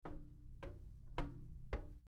Footsteps On Metal 02
Footsteps_on_metal_02.mp3